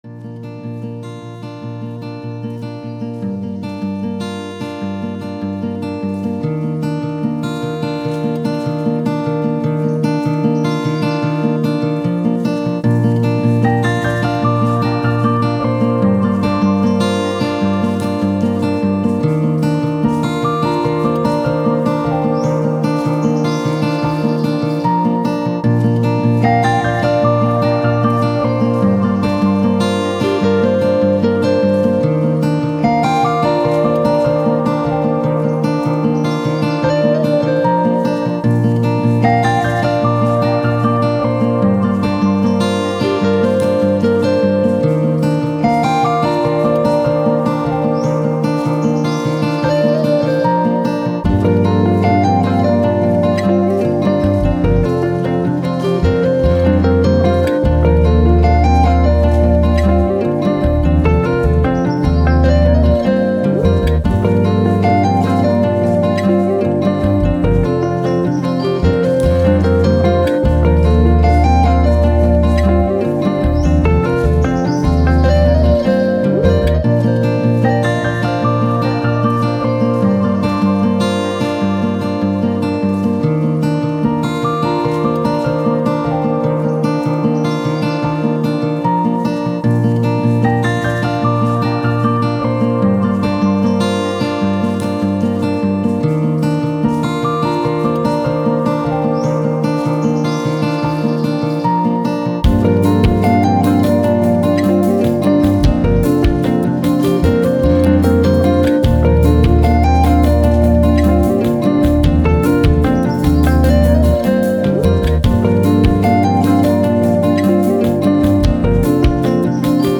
Soundtrack, Acoustic, Emotive, Morning, Guitars